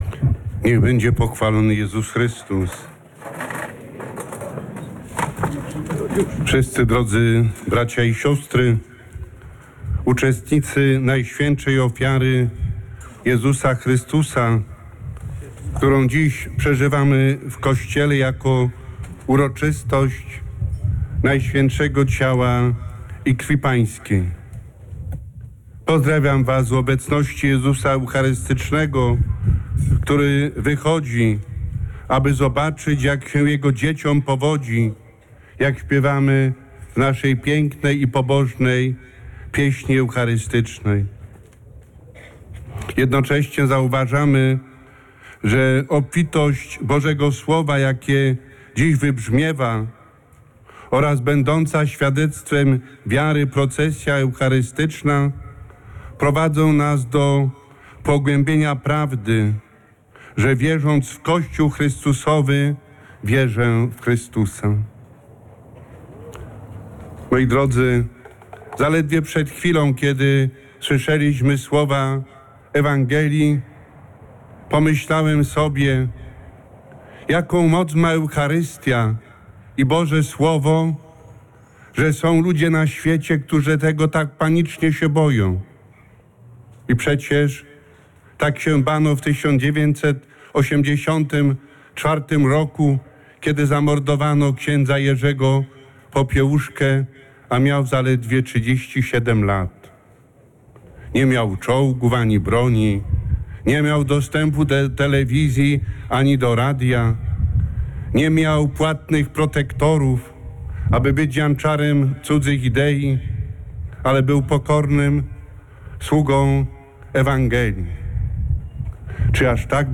W homilii wygłoszonej przed bazyliką katedralną hierarcha, nawiązując do ewangelii św. Jana i ostatniej wieczerzy stwierdził, że eucharystia ma wielką moc.